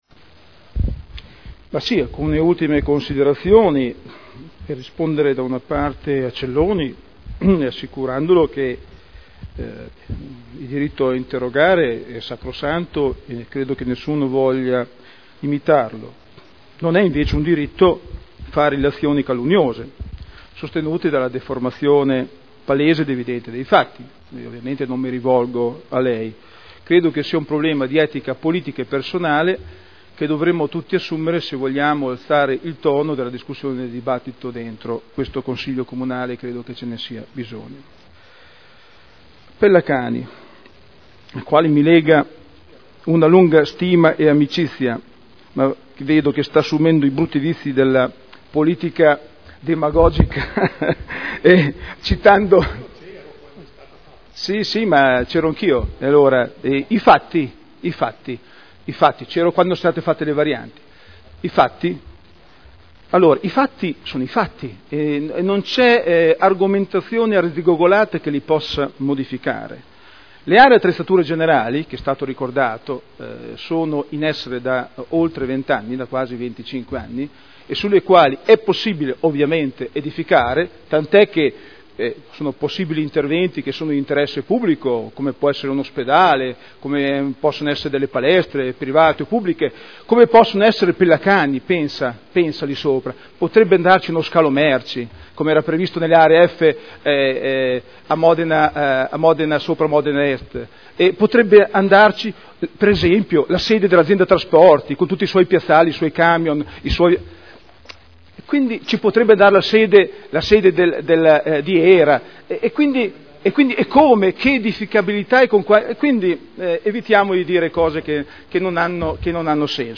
Daniele Sitta — Sito Audio Consiglio Comunale
Seduta del 19/09/2011.